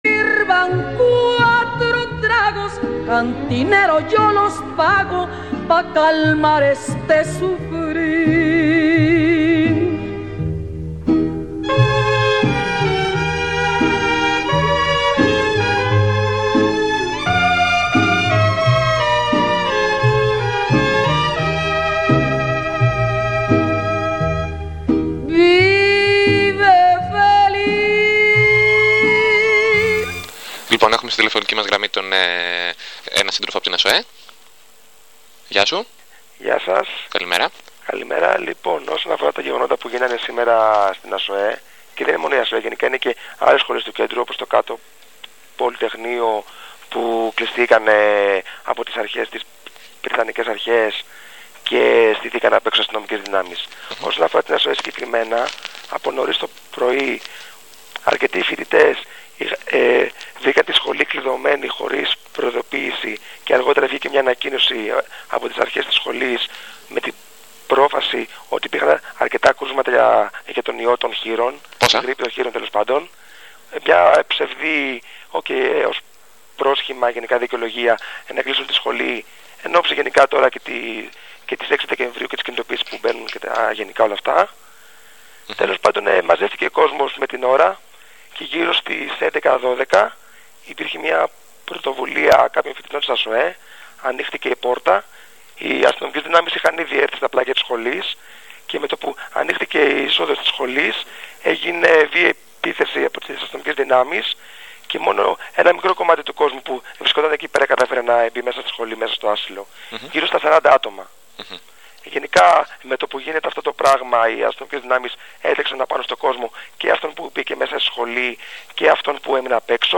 Τηλεφωνική ενημέρωση από φοιτητή της ΑΣΣΟΕ για τον αποκλεισμό της σχολής από την πρυτανεία και από τα ΜΑΤ που συνέβει την Πέμπτη 3-12-09 το πρωί, τη μέρα που ο σύλλογος φοιτητών είχε τη γενική του συνέλευση εν όψει των κινητοποιήσεων για το φετινό Δεκέμβρη.